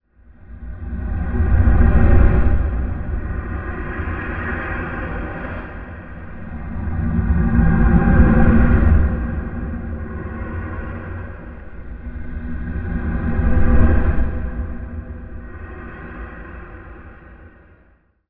rumble1.wav